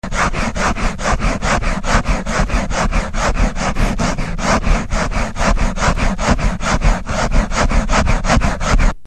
Sega a mano
Rumore di sega a mano che taglia materiale tipo legno.
SAWING.mp3